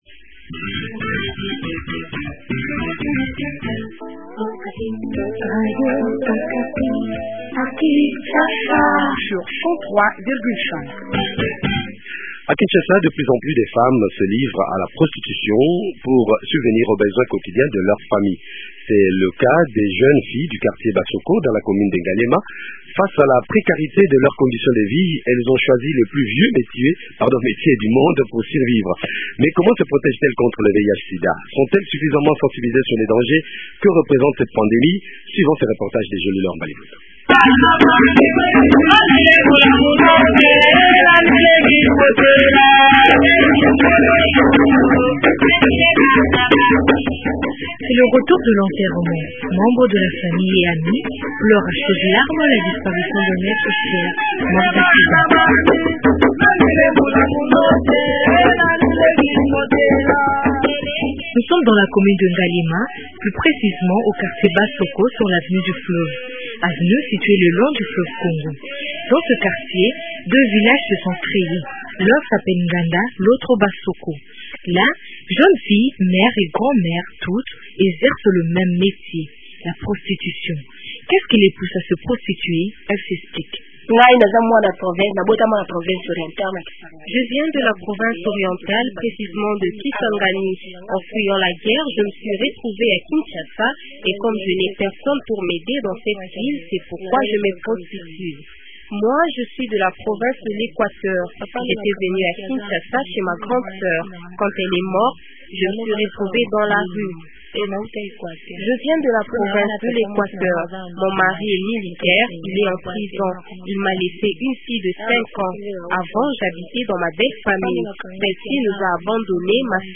Philomene Omatuku, ministre du genre, famille, intervent également sur la question reconnait que un grand travail reste à faire . A suivre dans cet entretien.